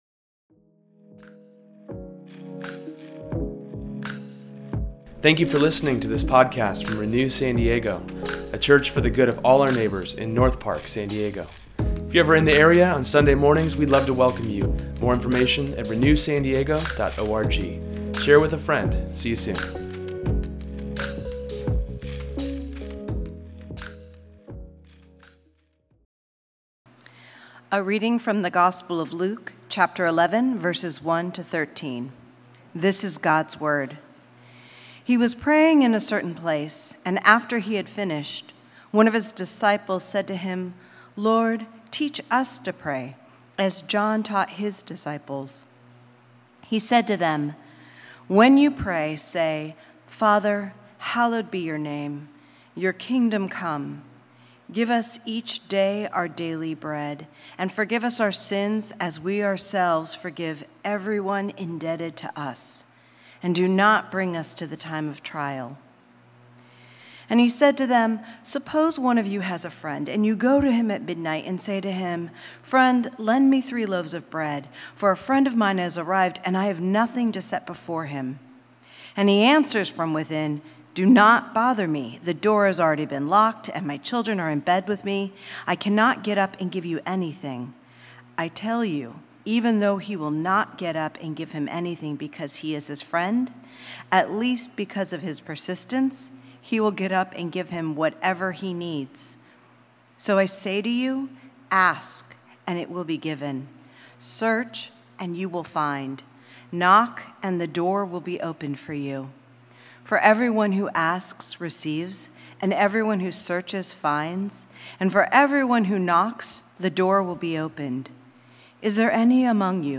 Today’s sermon explores the importance of prayer, and how to pray to God, with three categories to help us.